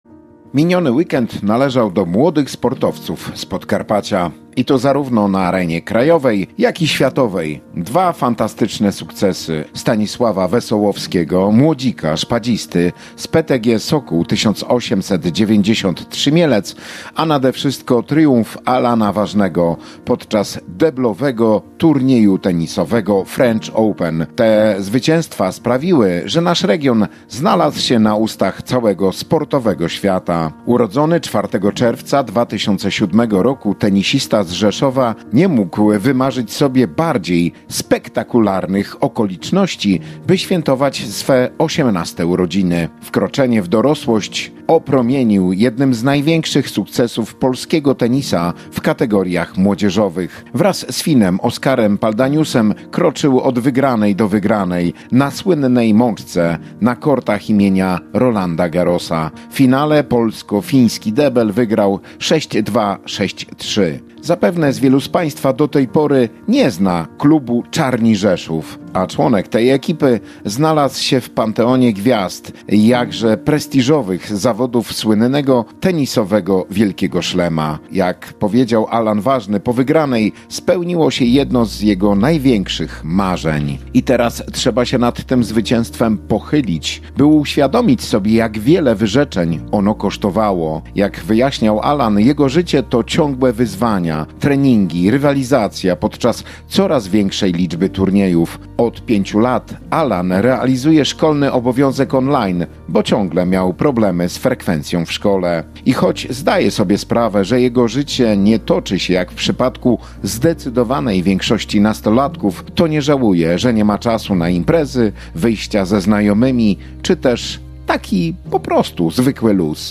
Miniony weekend należał do młodych sportowców z Podkarpacia • Felieton sportowy • Polskie Radio Rzeszów